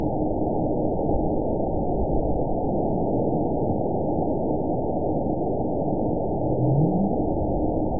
event 920558 date 03/30/24 time 00:33:19 GMT (1 year, 1 month ago) score 9.52 location TSS-AB02 detected by nrw target species NRW annotations +NRW Spectrogram: Frequency (kHz) vs. Time (s) audio not available .wav